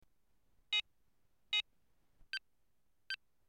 Die Signalisierung erfolgt über einen Piepston, der recht tief eingestellt ist, weil das nach unseren Tests als weniger störend empfunden wird. Er liegt nahe am Kammerton a (440Hz).
Hier ist ein Klangbeispiel mit 2 tiefen Signaltönen und 2 hohen Wiederholungspiepsern:
mini-meditationsuhr-ton.mp3